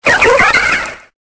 Cri de Keldeo Aspect Normal dans Pokémon Épée et Bouclier.